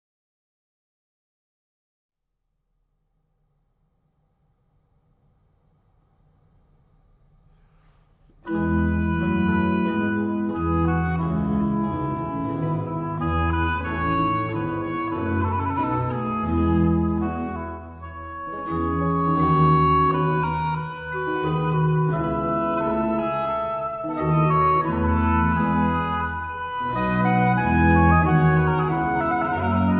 Instruments d'epoque
• Registrazione sonora musicale